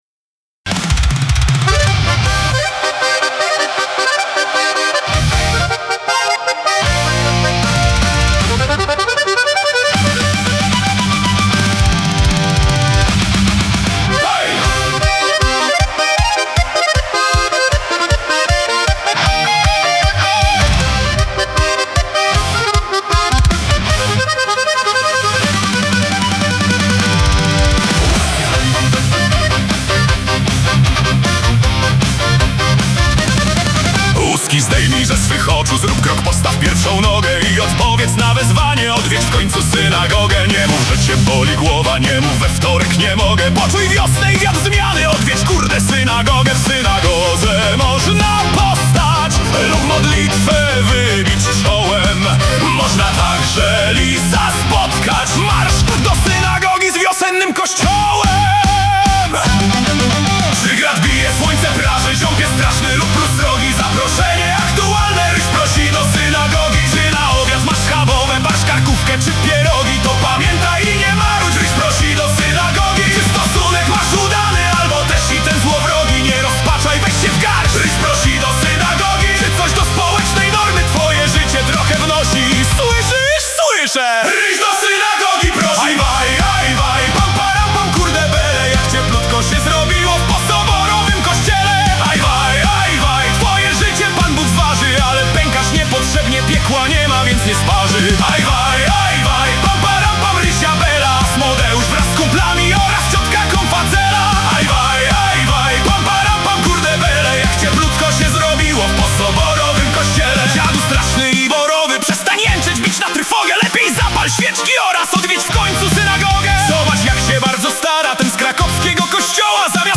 produkcja AI.